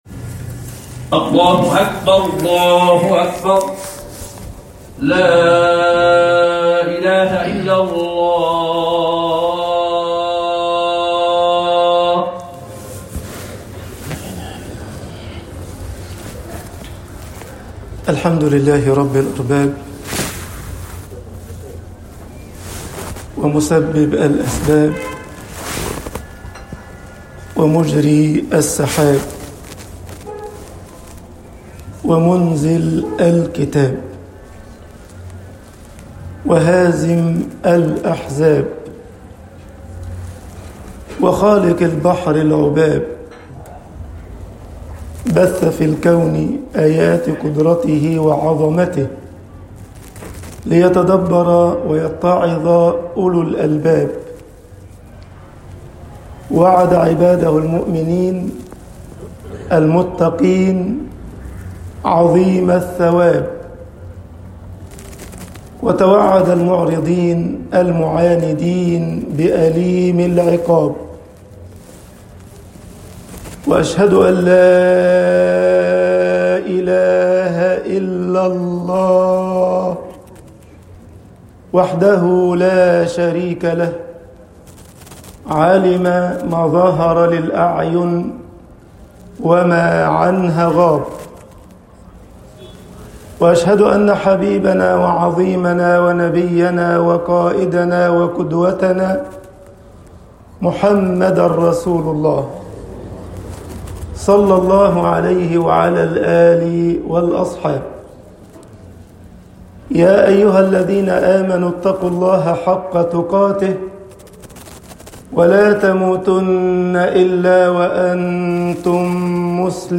خطب الجمعة - مصر حَظُ المُؤمنِ ثَلاثٌ طباعة البريد الإلكتروني التفاصيل كتب بواسطة